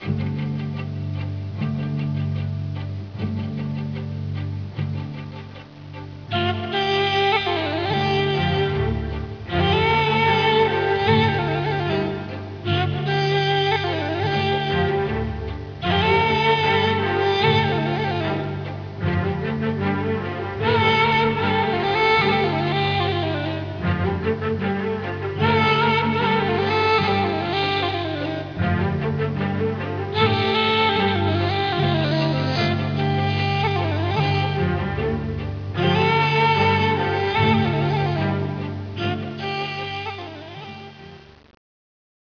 Persian music
which can sound both warm and crisp to the western listener.